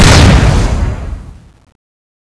Index of /server/sound/weapons/tfa_cso/laserfist
shootb_exp.wav